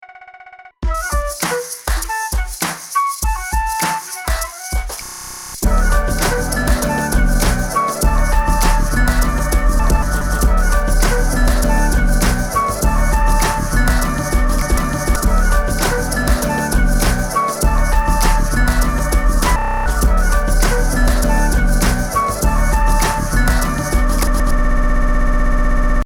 MIDIキーボードの鍵盤やツマミをいじるだけでスクラッチ音や一部分の音のループ、または徐々に音が遅くなっていくなどのエフェクトを操作できてしまいます。
緩い系
ちょっとしたグリッチ音を作成する時も重宝しそうです。